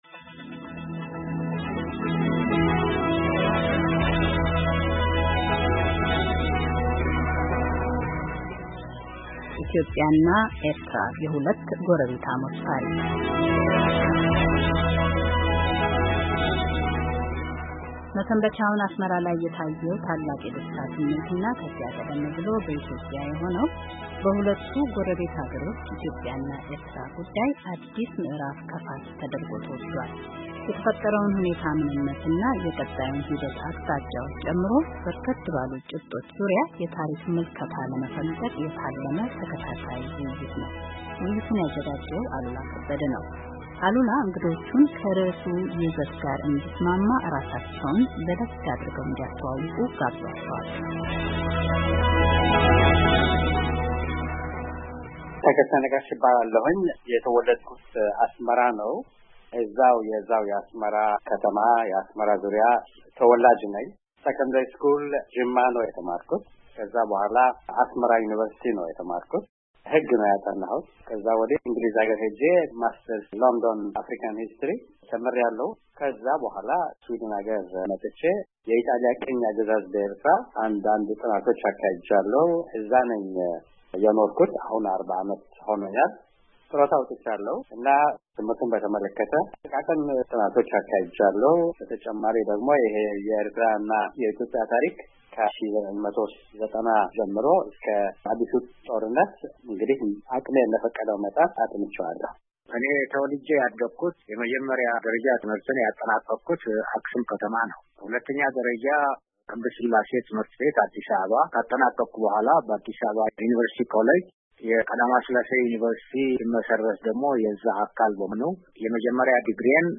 የሁለት ጎረቤታሞች ታሪክ .. ቆይታ ከሦሥት የታሪክ አጥኝዎች ጋር ..